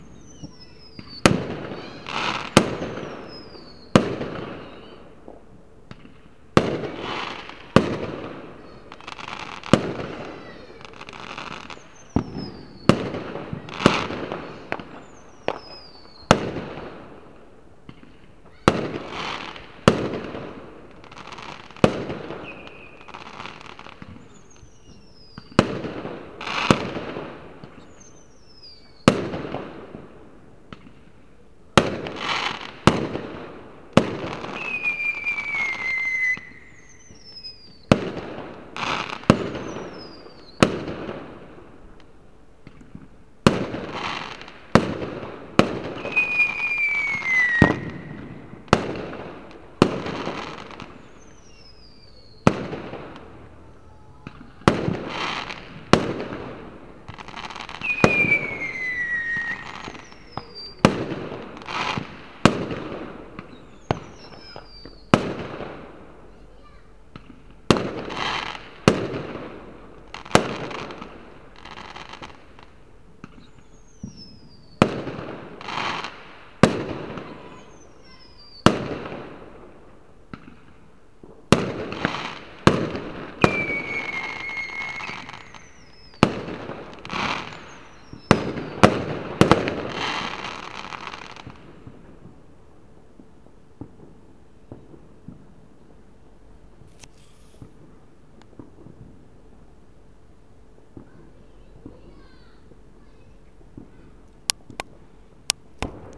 Fireworks display 2
explosion explosions fireworks Fireworks rockets sound effect free sound royalty free Voices